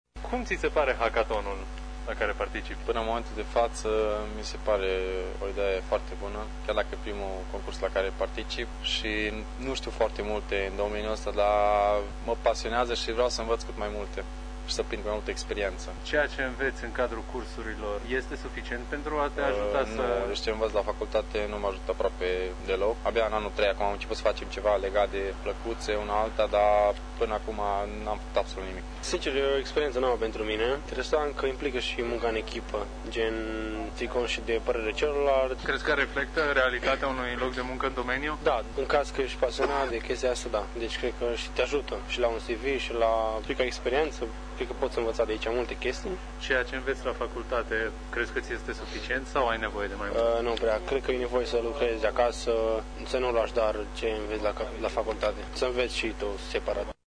Studenții sunt încântați de participarea la eveniment și spun că ceea ce învață la cursuri nu este suficient pentru a atinge un nivel de performanță în domeniul IT: